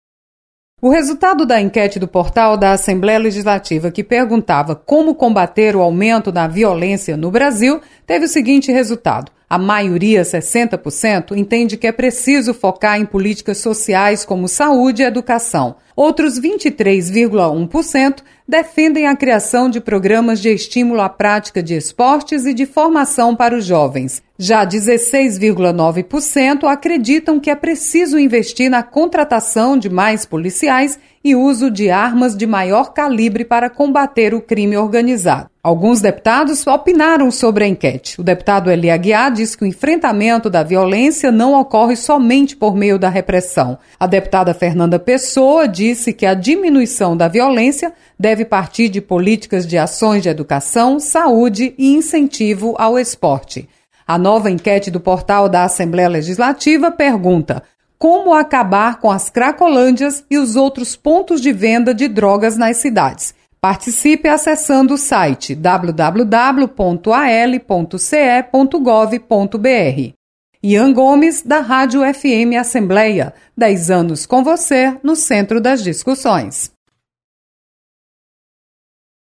Internautas defendem políticas sociais para combater a violência. Repórter